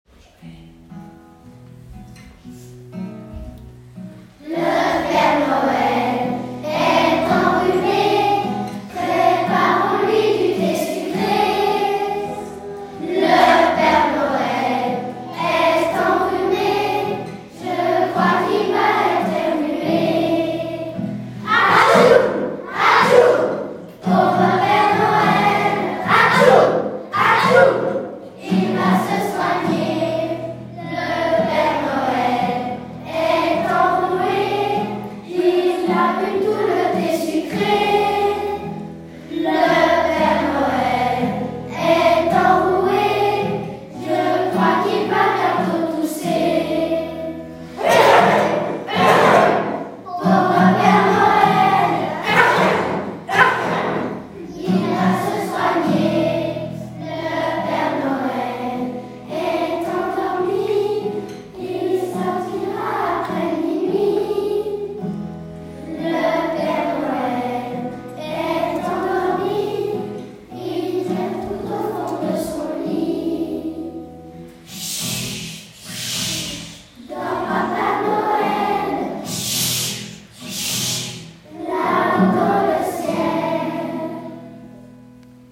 2021-22 : “Chantée de Noël”, les classes de Corcelles
Groupe 1 : classes 1-2P44, 5P42 et 6P41